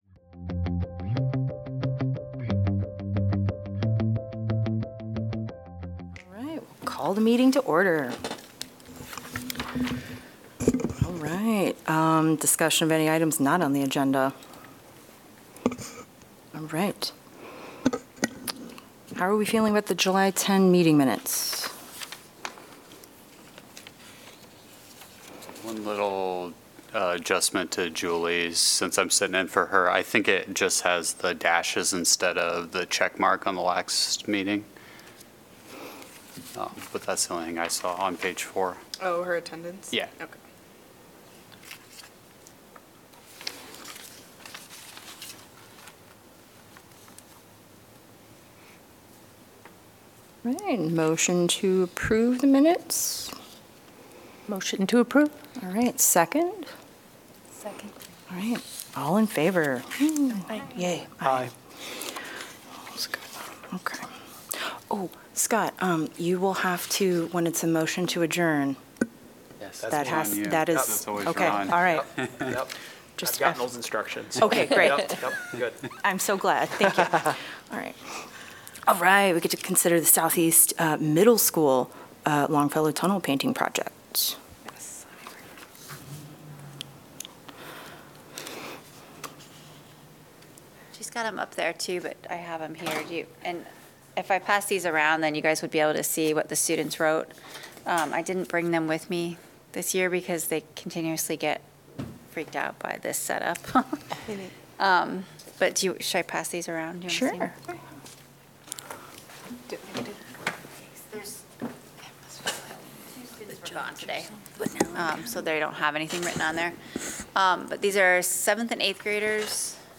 The regular monthly meeting of the Public Art Advisory Committee.